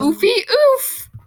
Woman Oofy Oof Sound Effect Free Download
Woman Oofy Oof